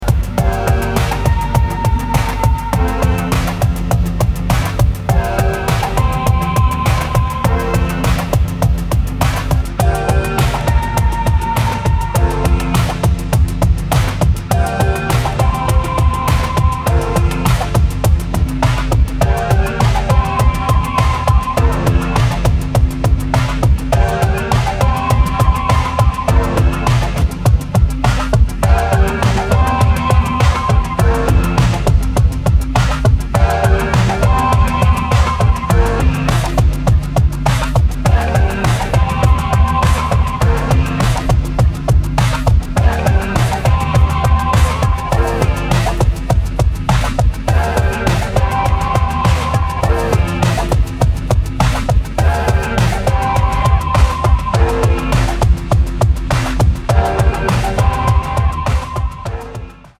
梦幻童话的，2分钟